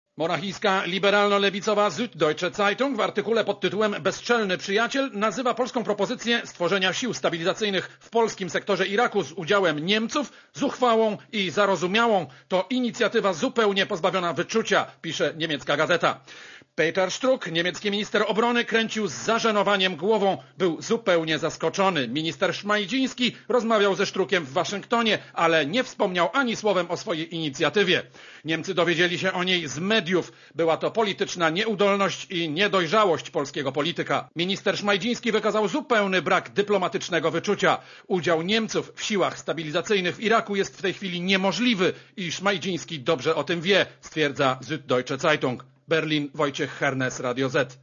Korespondencja z Berlina (190Kb)